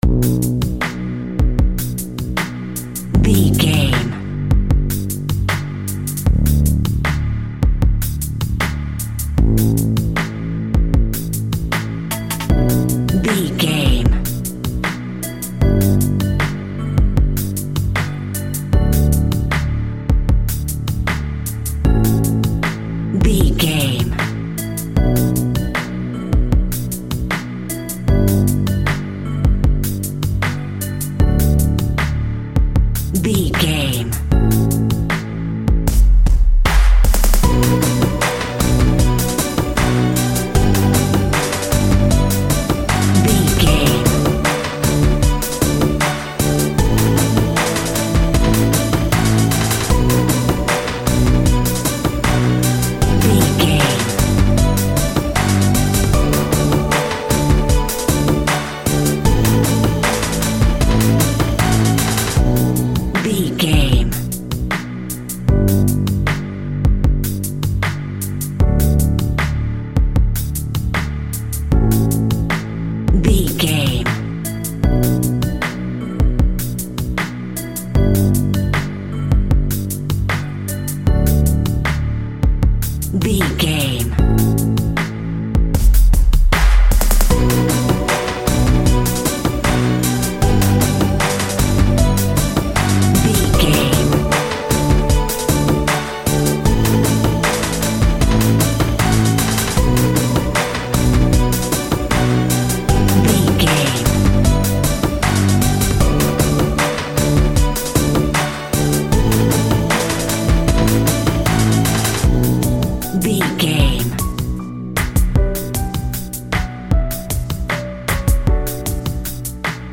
Aeolian/Minor
instrumentals
chilled
laid back
groove
hip hop drums
hip hop synths
piano
hip hop pads